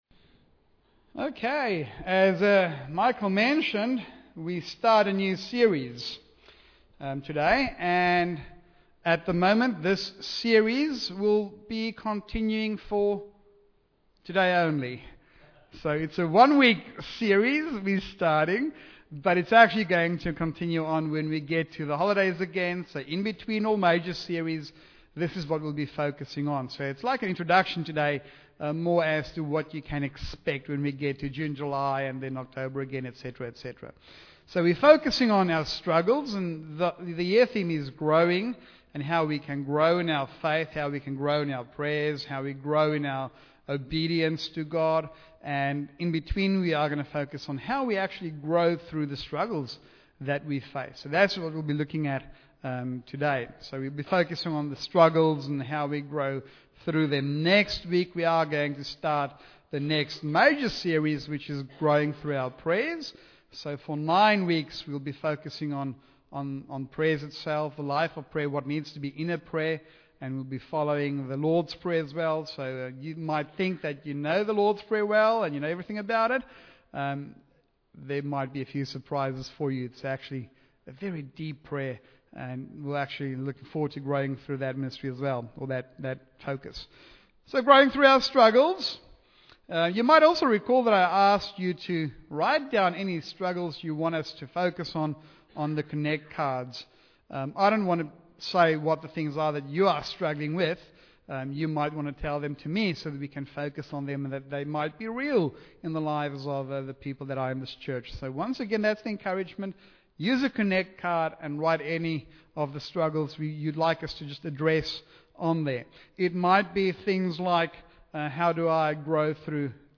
Bible Text: James 1:1-18 | Preacher